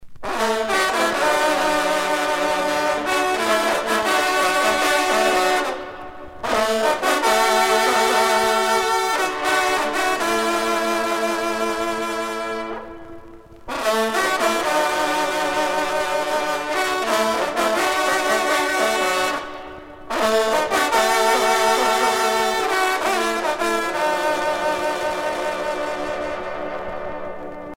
trompe - fanfare
circonstance : vénerie